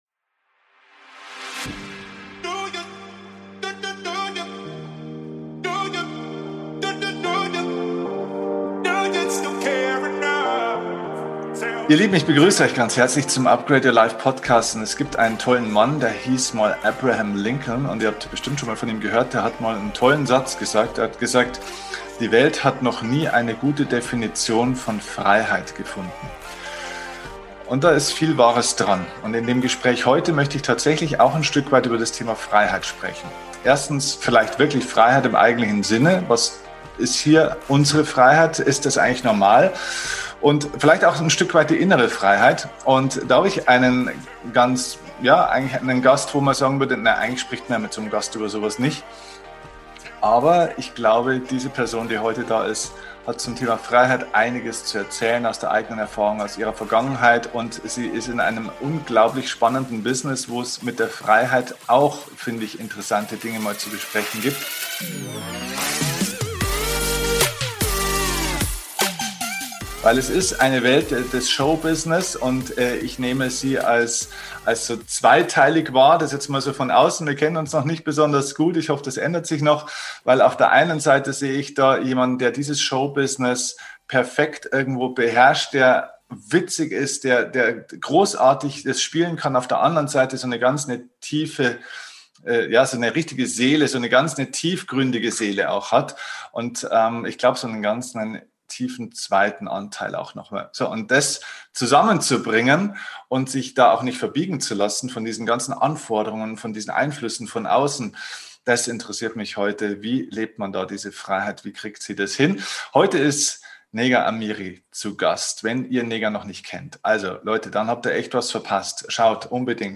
Viel Spaß mit diesem Interview mit dieser starken Frau!